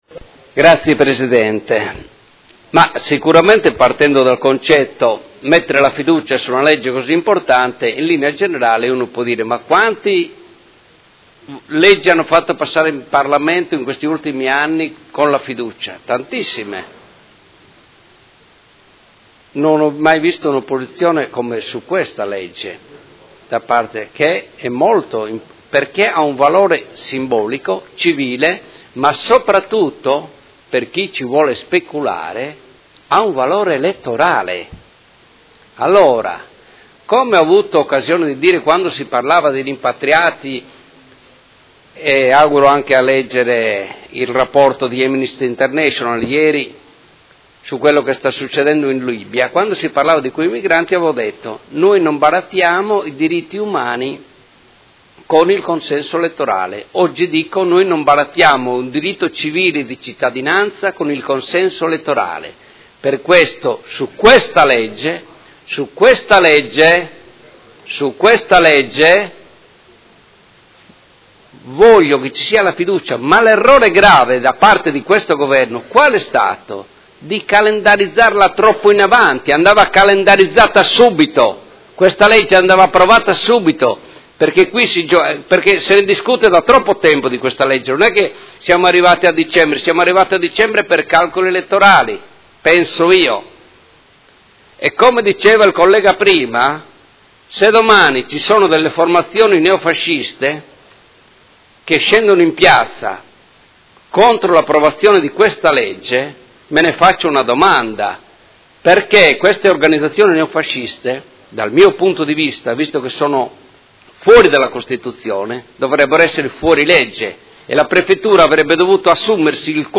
Seduta del 14/12/2017 Dibattito.